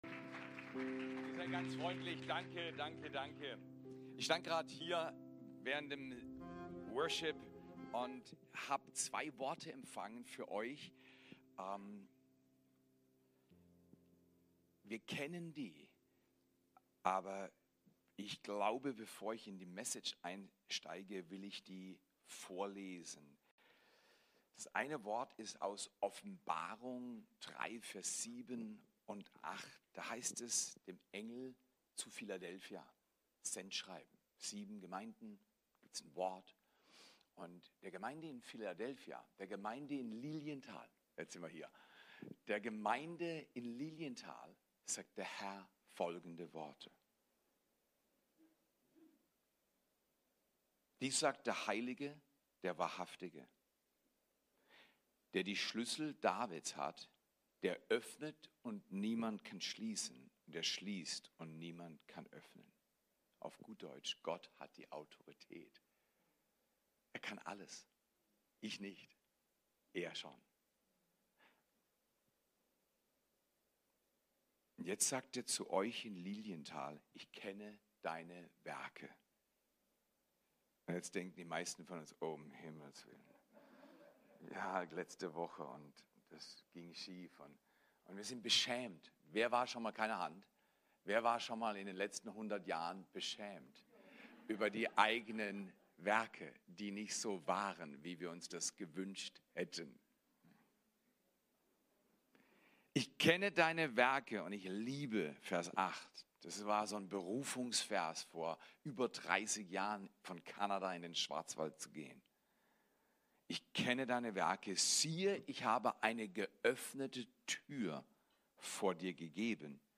Predigt: „Campen mit Gott“